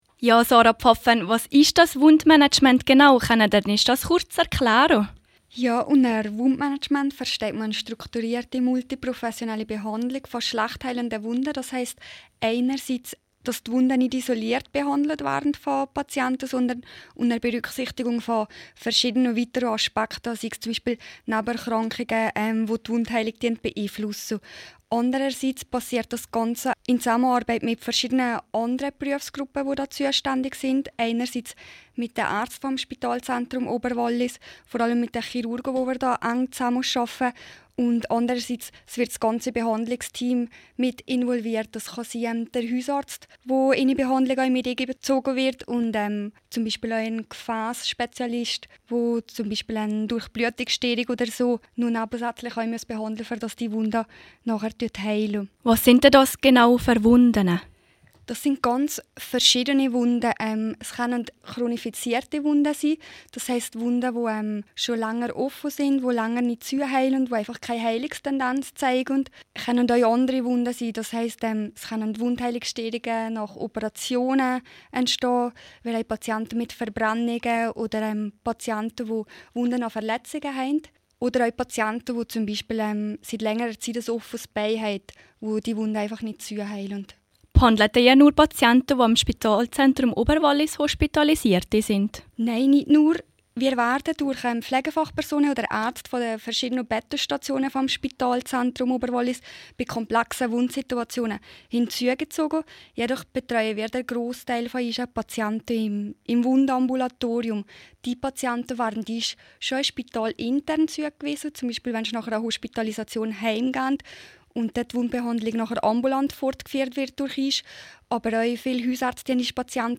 Auf rro spricht sie über ihre Arbeit.